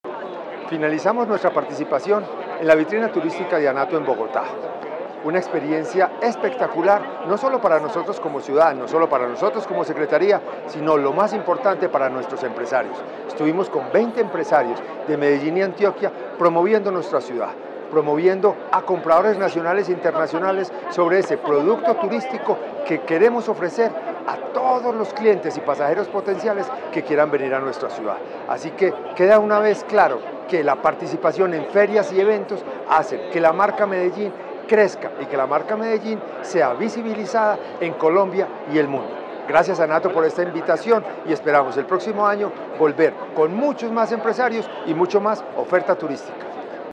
Palabras de José Alejandro González Jaramillo, secretario de Turismo y Entretenimiento de Medellín